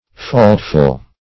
Faultful \Fault"ful\, a. Full of faults or sins.
faultful.mp3